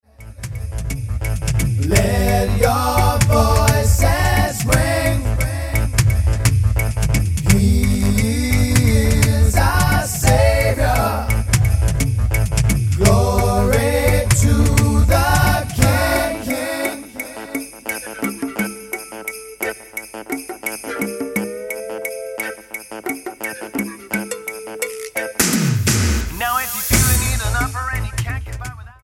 STYLE: Pop
a gutsy blues rock voice